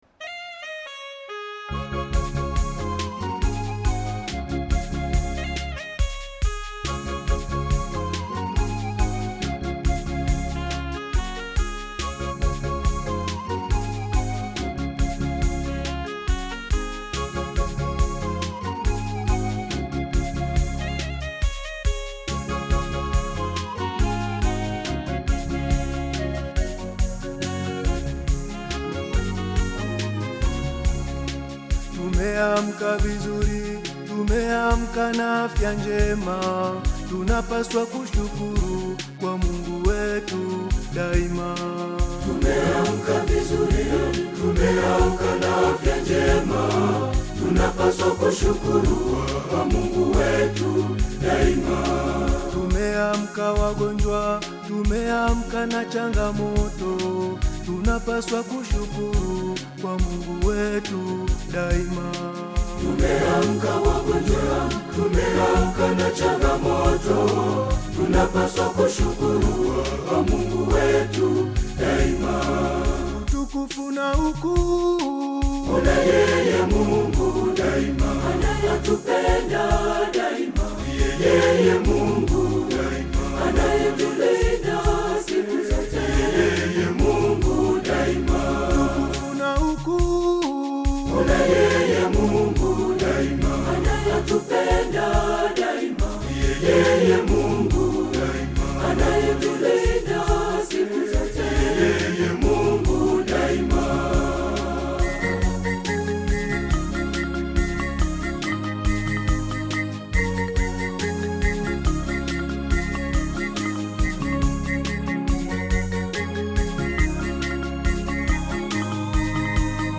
song of thanksgiving
African Music